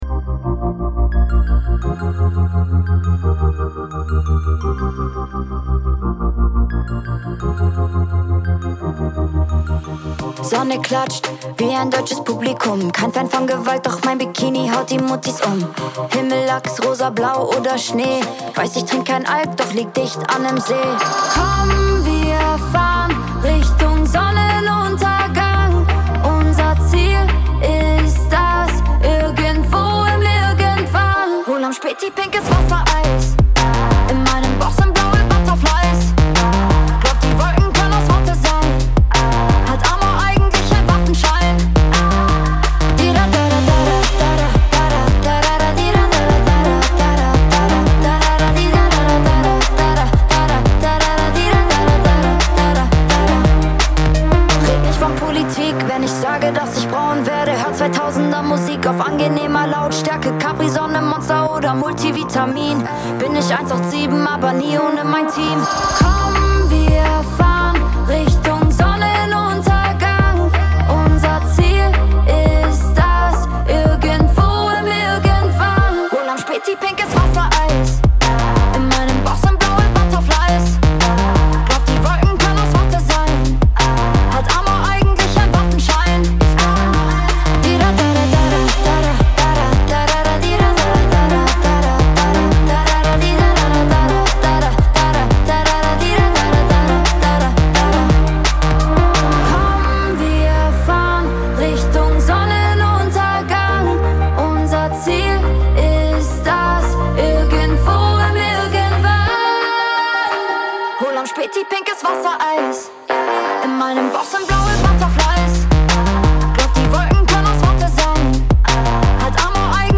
Pop GER